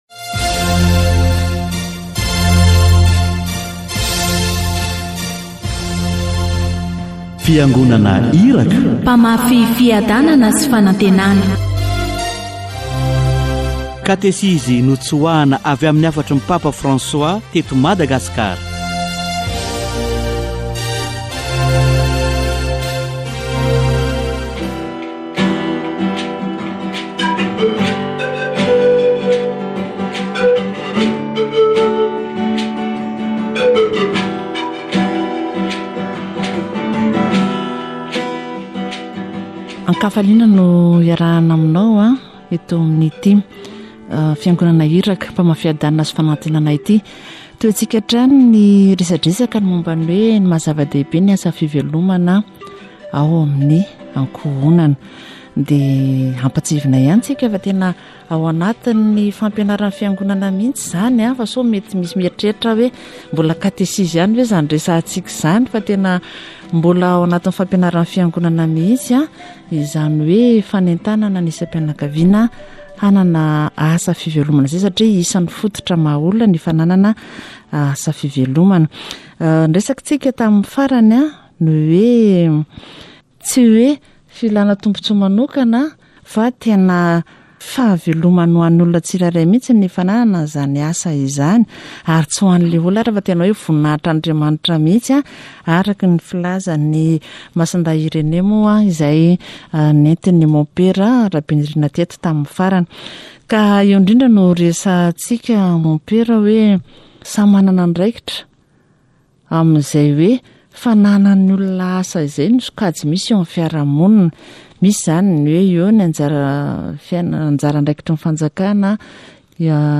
Everyone must aim and create these means of subsistence, these different activities must be coordinated so that the State or the designated authorities do the coordination. The objective is to avoid duplication despite the freedom of competition and the life of the inhabitants of Akamasoa is a model of this. Catechesis on livelihood